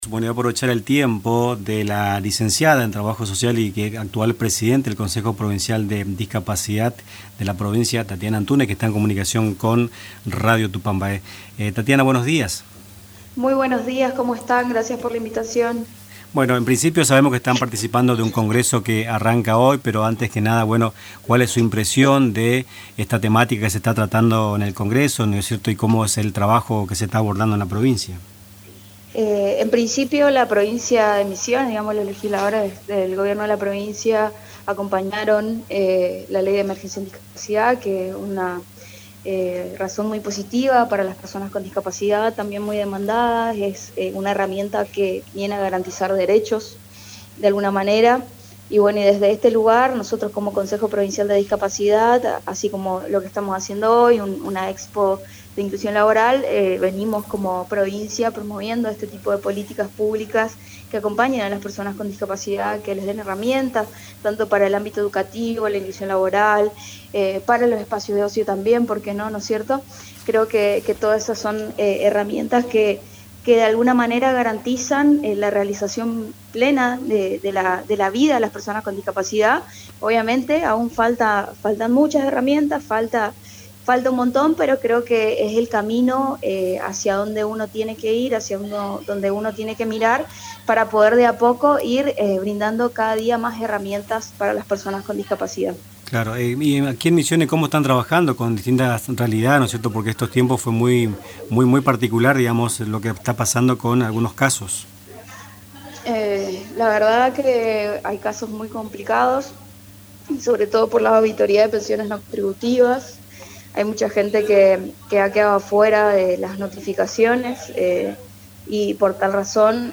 En “Nuestras Mañanas”, entrevistamos a la presidente del Consejo Provincial de Discapacidad de Vicegobernación, Lic. Tatiana Antúnez antes del inicio de un Congreso de Discapacidad y empleo que se desarrolla en el Parque del Conocimiento.